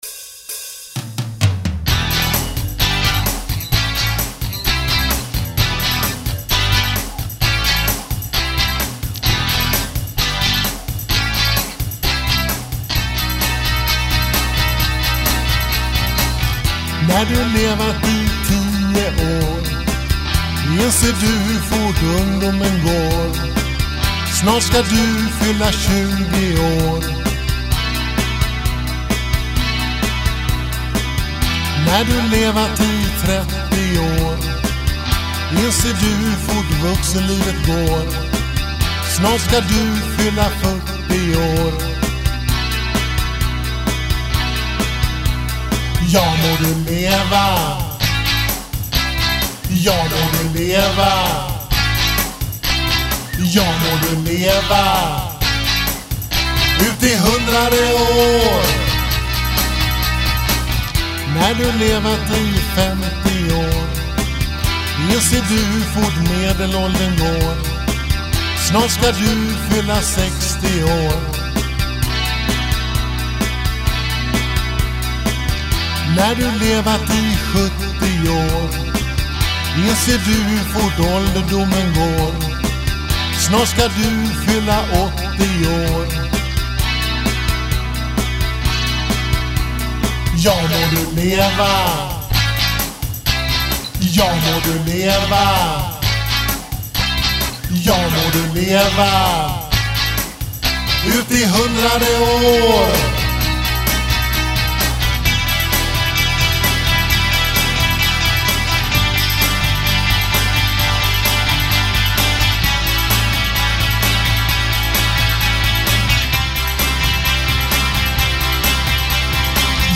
elgitarr